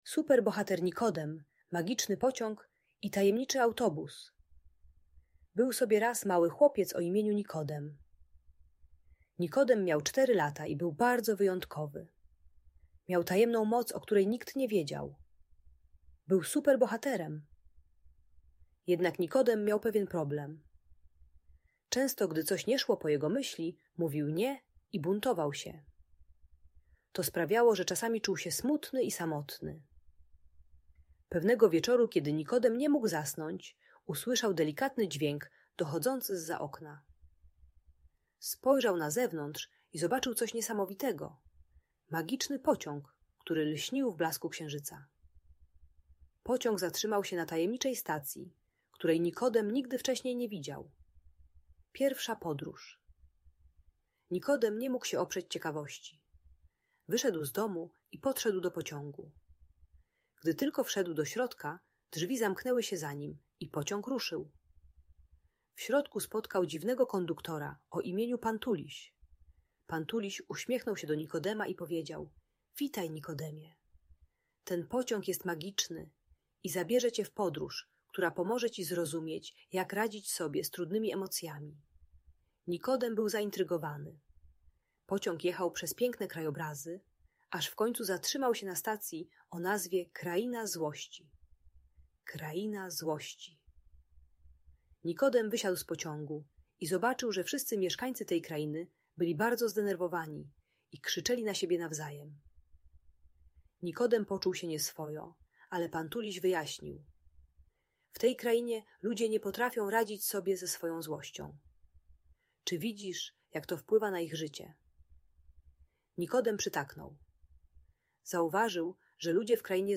Super Bohater Nikodem i Magiczny Pociąg - Audiobajka dla dzieci